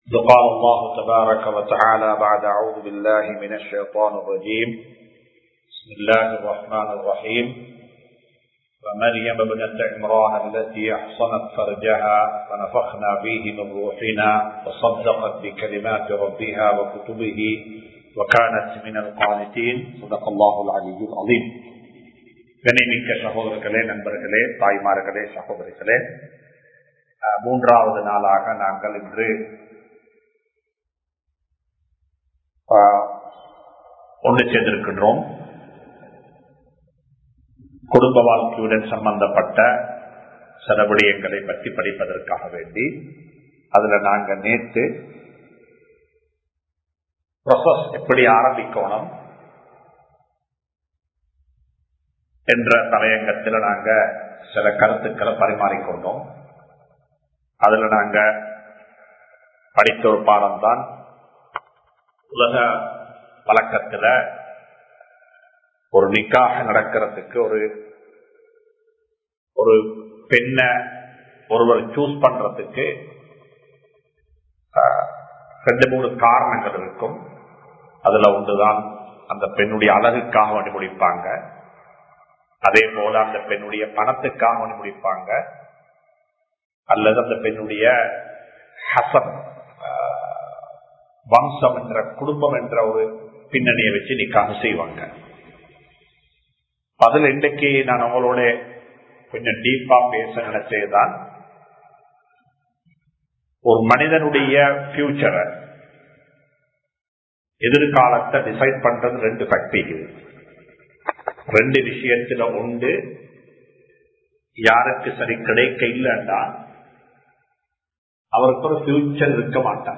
கணவன் மனைவியின் பண்புகள் (Day 03) | Audio Bayans | All Ceylon Muslim Youth Community | Addalaichenai